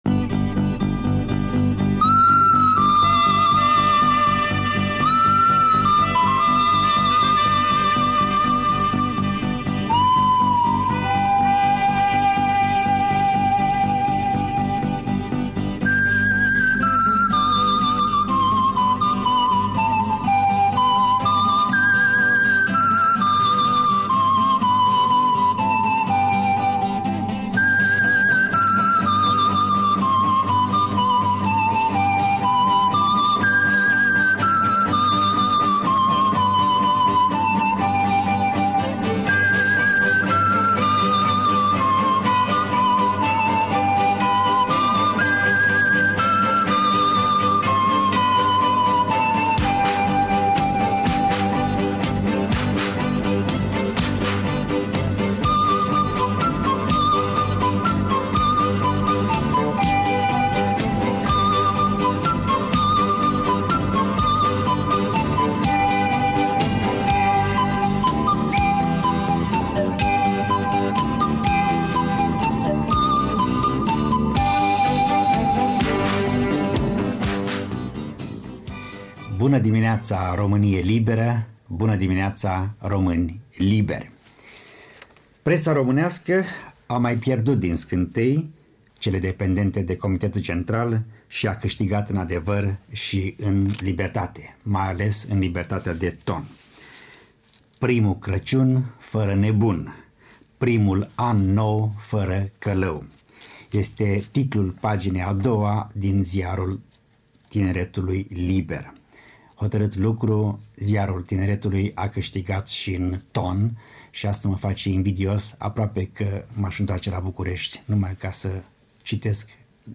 Un fragment din emisiunile Studioul special al „Actualității românești”, radio Europa Liberă, 27 decembrie 1989.